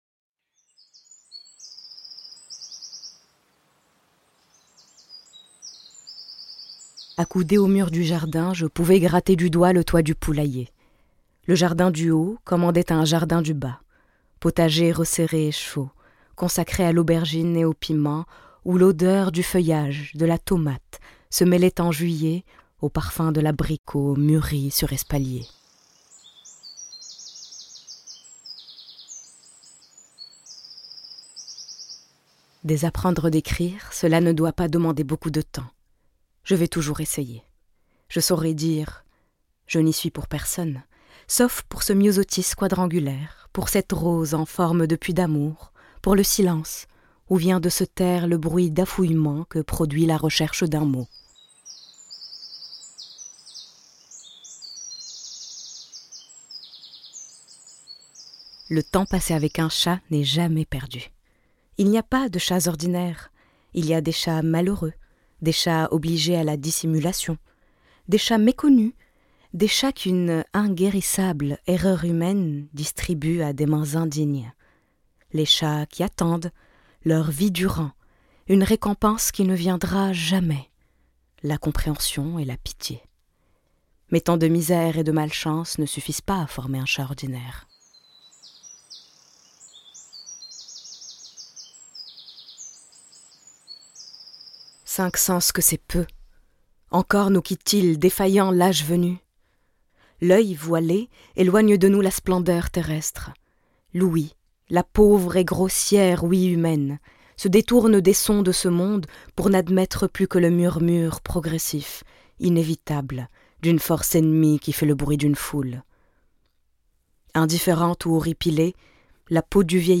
Bandes-son
Voix off
Narratrice